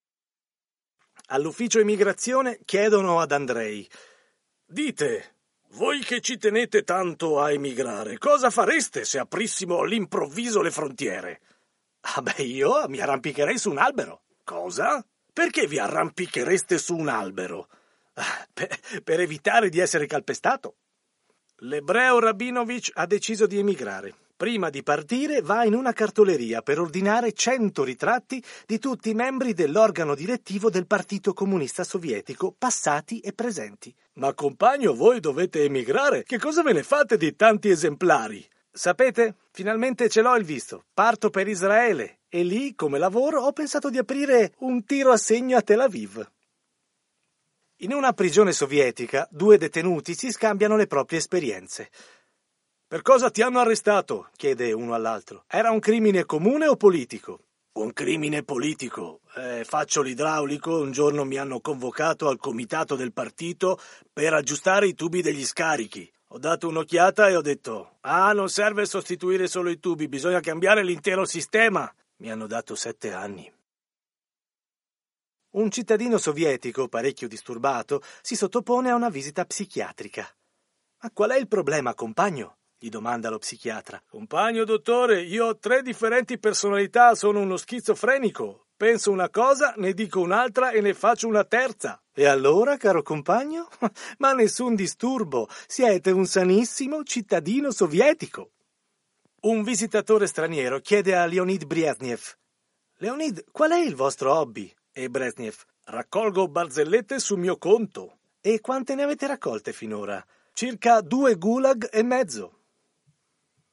5 barzellette targate Unione sovietica. Fanno parte della mostra “Autobiografia di un mondo ex” promossa dal Gioco degli Specchi e in questi giorni a Trento.